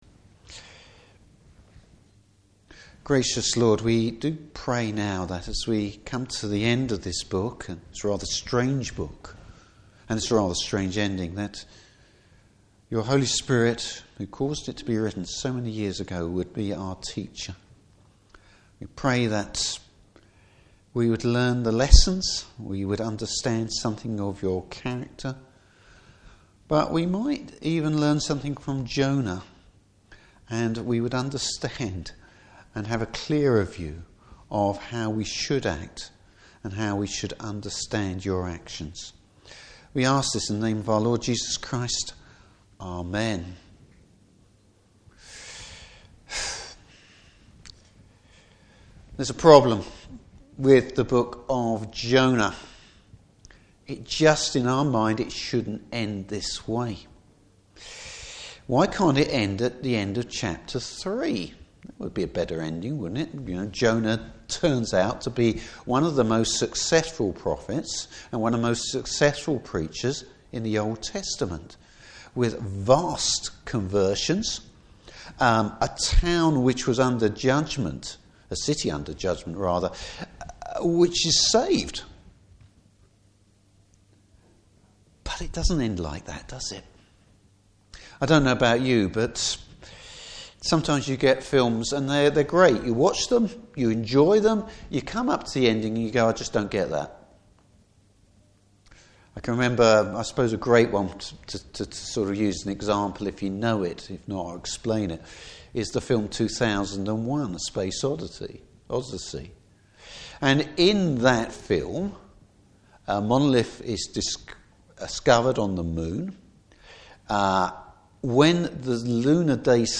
Passage: Jonah 4 Service Type: Evening Service How do we understand Jonah’s anger in the light of the rest of the book?